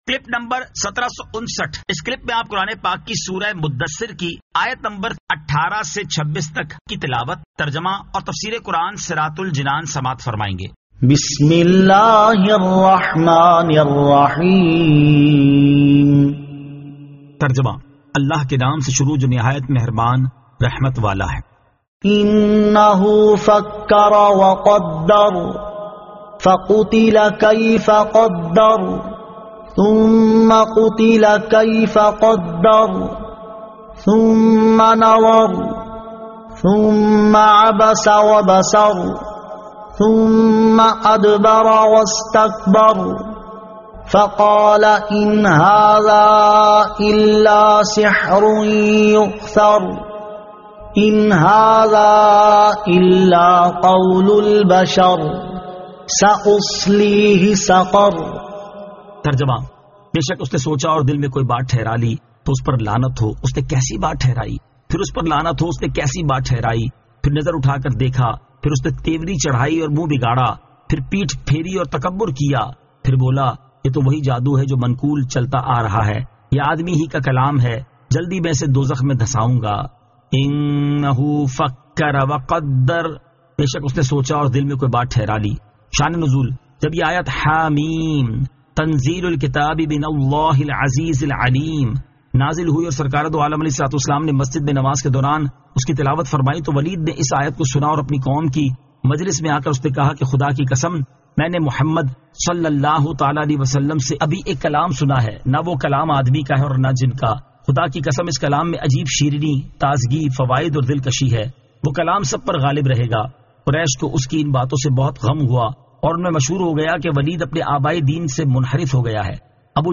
Surah Al-Muddaththir 18 To 26 Tilawat , Tarjama , Tafseer